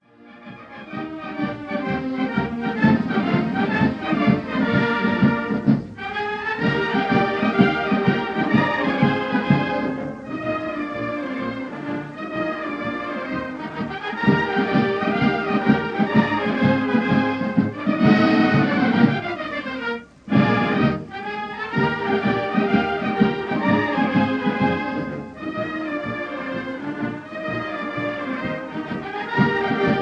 Massed bands of the
conducted by bandmaster
Recorded in Rushmoor Arena
Aldershot June 1933